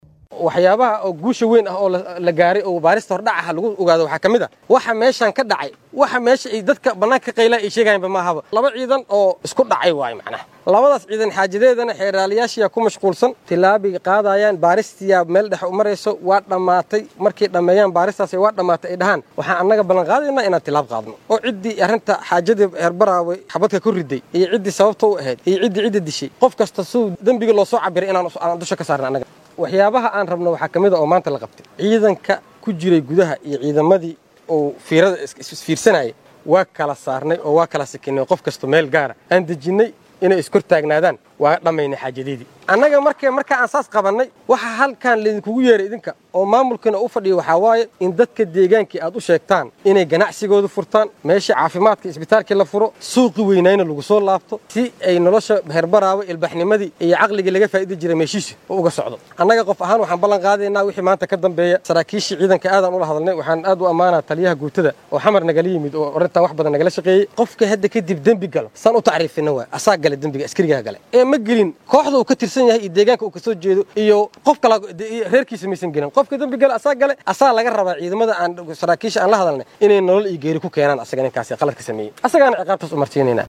Guddoomiyaha maxkamadda ciidamada qalabka sida ee Soomaaliya Xasan Cali Nuur Shuute ayaa ka hadlay dagaal dhawaan ka dhacay magaalada Baraawe ee caasimadda maamulka Koonfur Galbeed.